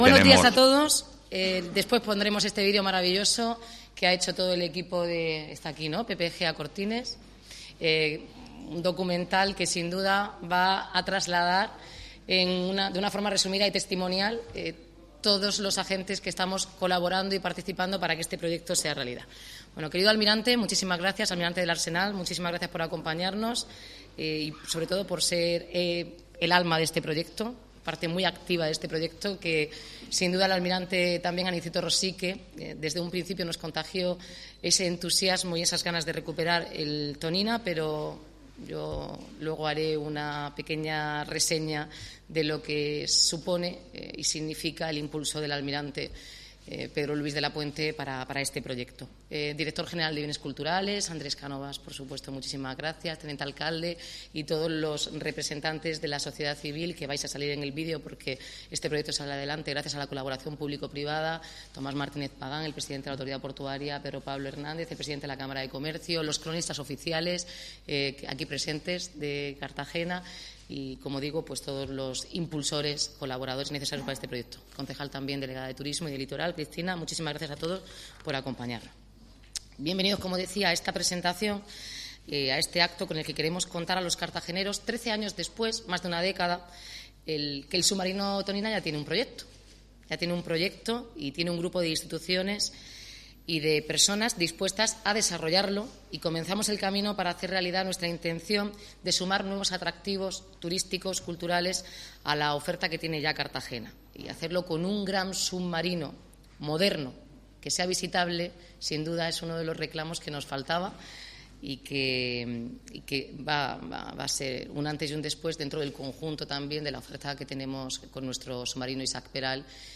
Audio: Presentaci�n del Proyecto de Musealizaci�n del submarino Tonina (MP3 - 31,84 MB)